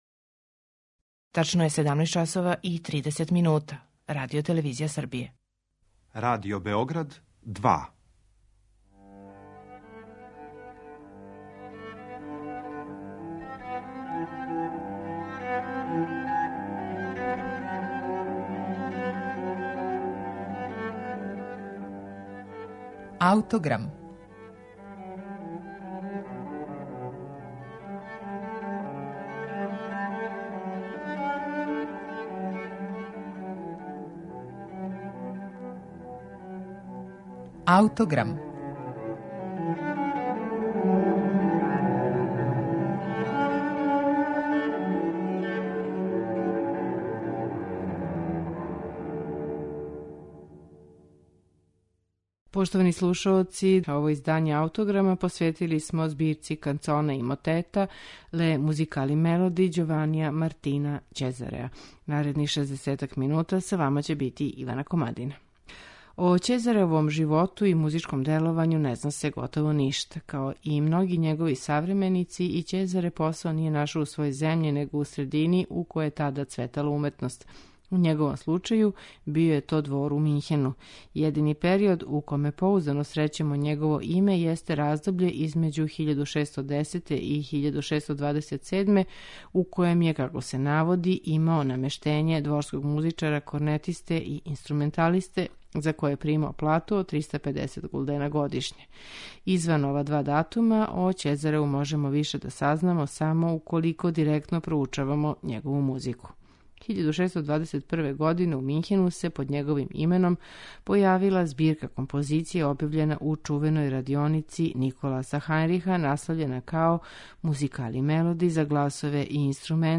Од 28 нумера које су наведене у садржају, 14 су духовни концерти за један до пет гласова са басо континуом. Уз њих стоји исти број инструменталних комада за један до шест гласова са басом континуом.
Композиције из ове збирке слушаћете у интерпретацији чланова ансамбла Les Sacqueboutiers из Тулуза.